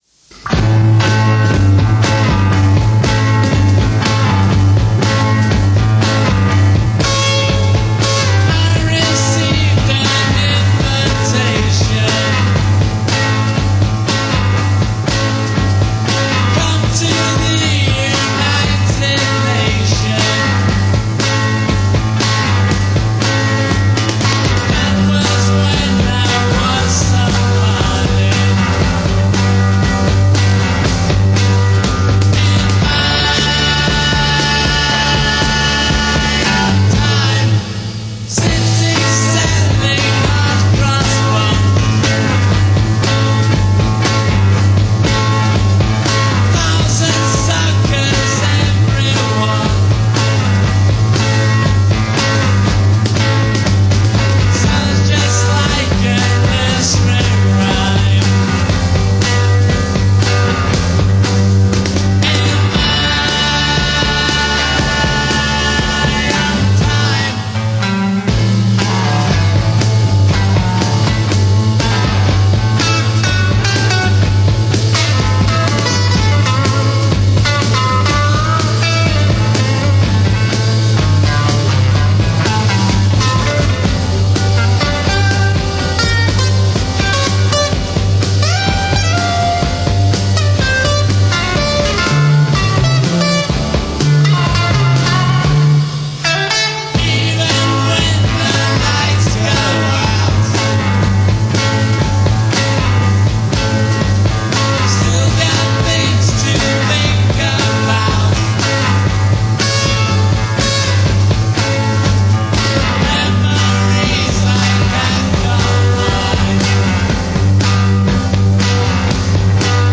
In a session recorded for Swedish radio on July 21, 1967.